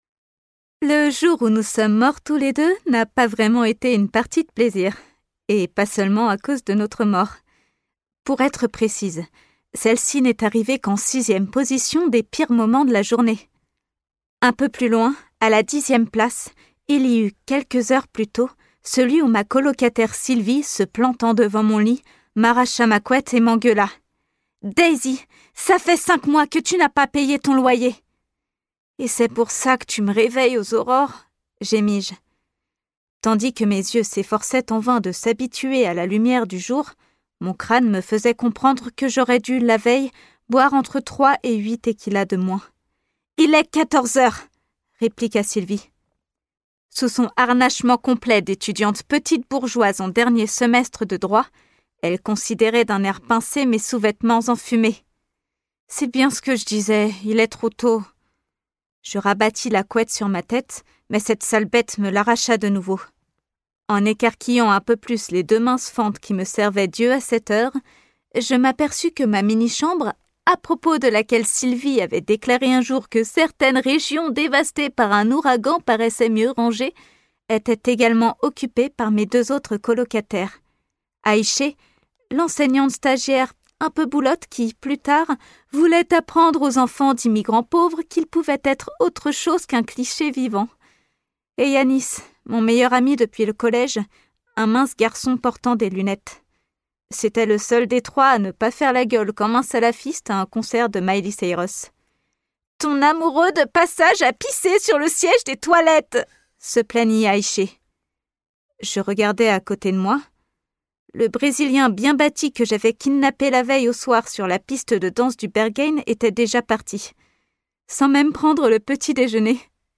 Narration - comédie
5 - 30 ans - Soprano